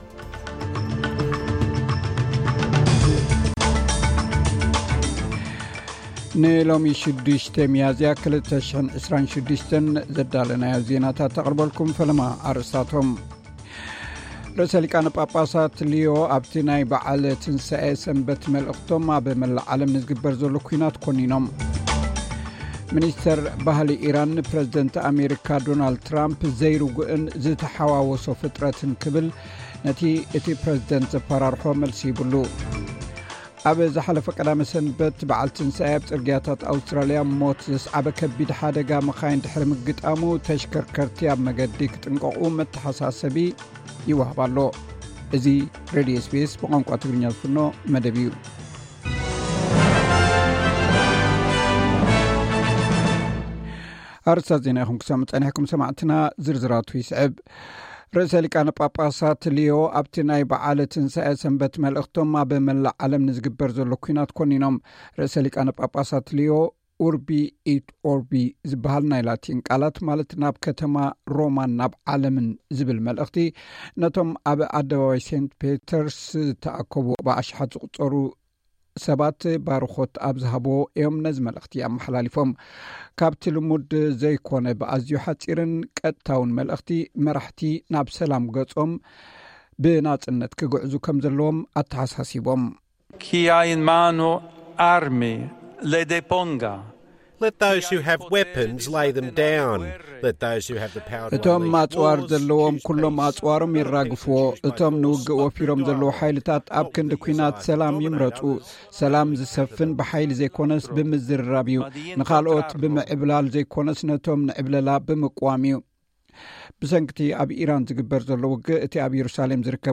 ዕለታዊ ዜና SBS ትግርኛ (06 ሚያዝያ 2026)